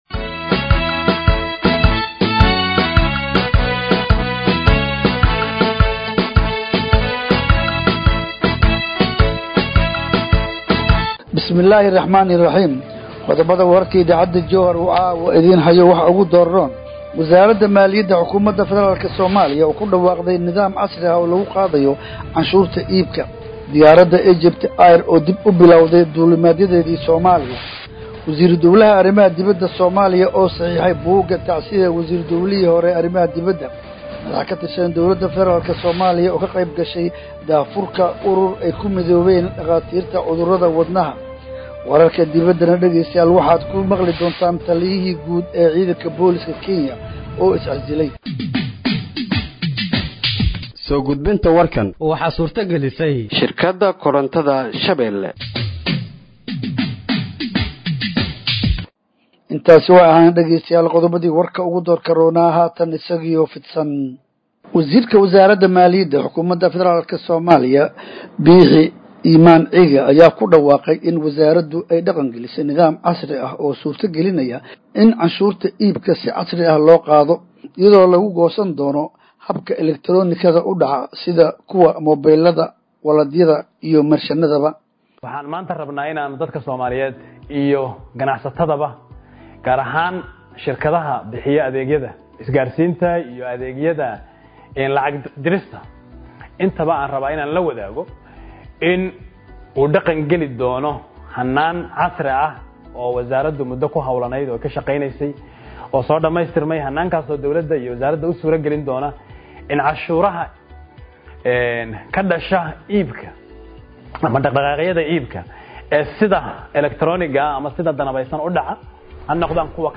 Warka habeenimo ee radiojowhar